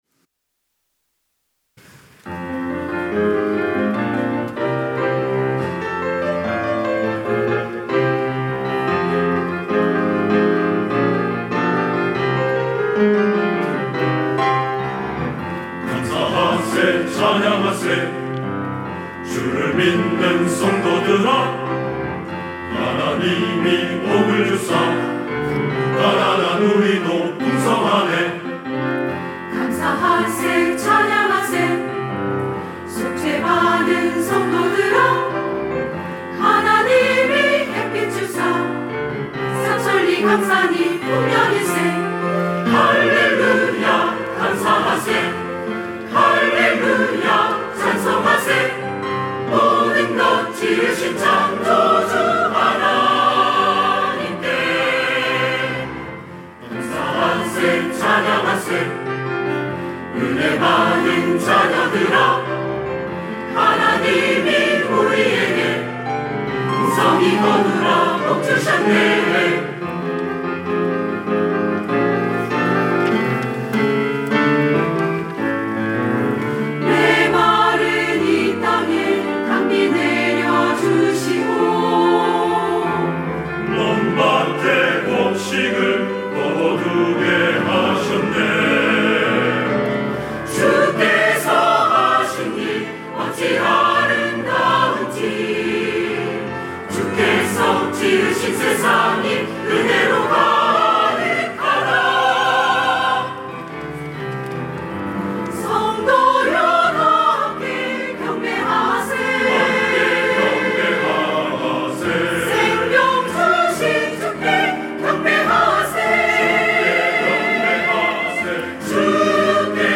할렐루야(주일2부) - 감사하세 찬양하세
찬양대